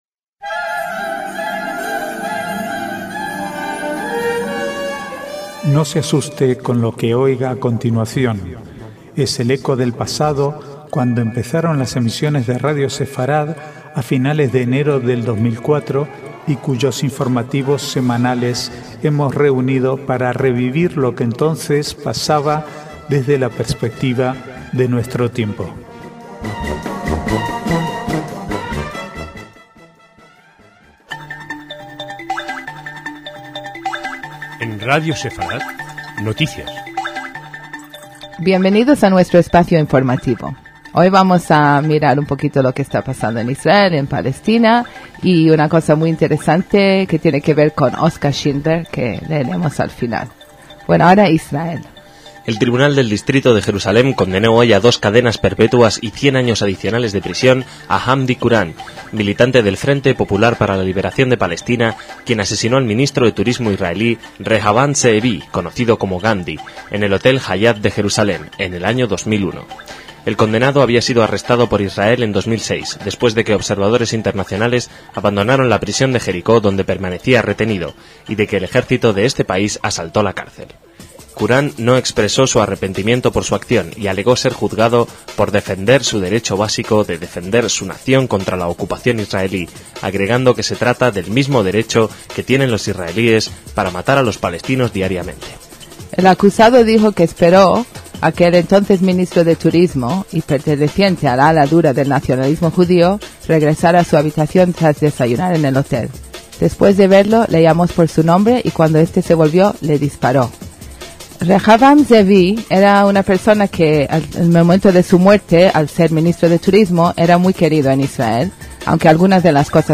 Archivo de noticias del 4 al 6/12/2007